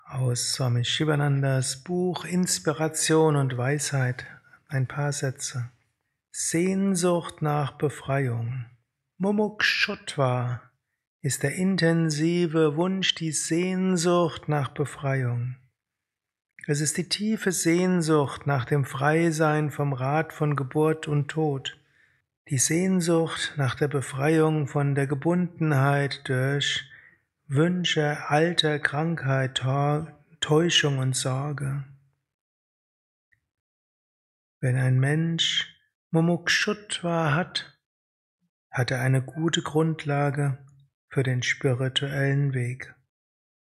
Kurzvorträge
Dies ist ein kurzer Vortrag als Inspiration für den
eine Aufnahme während eines Satsangs gehalten nach einer